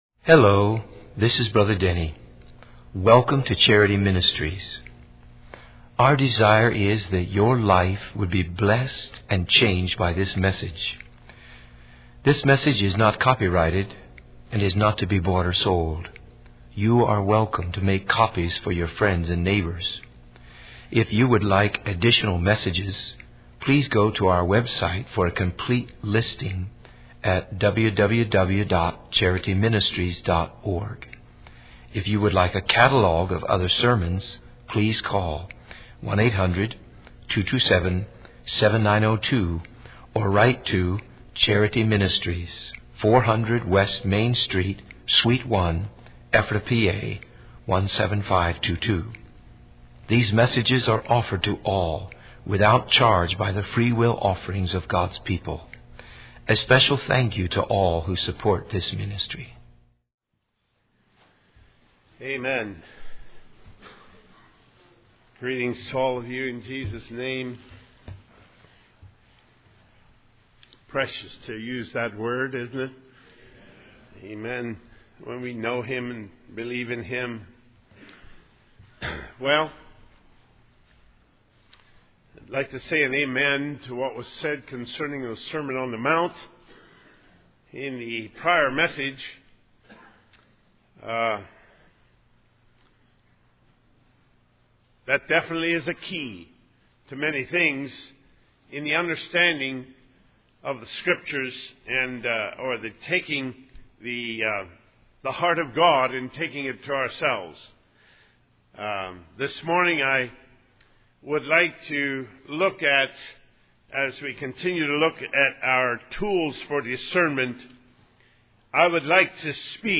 In this sermon, the speaker discusses the importance of interpreting and applying scripture correctly.